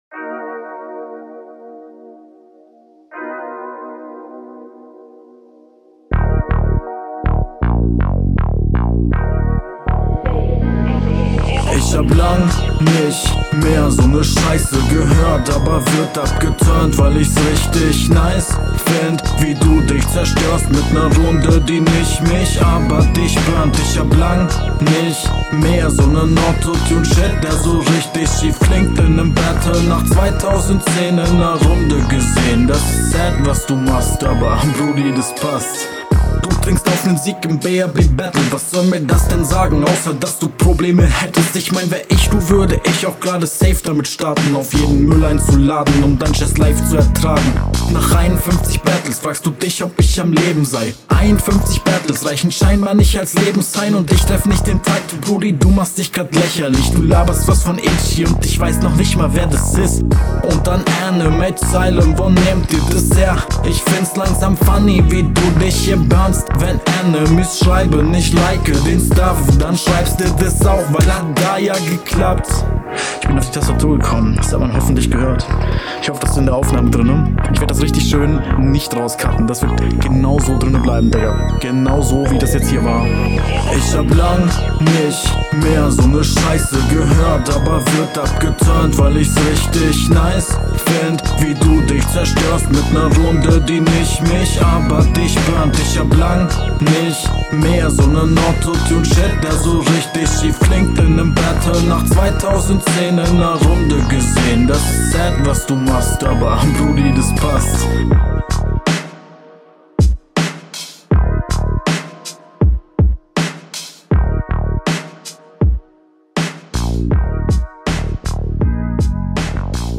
Der Flow klingt deutlich safer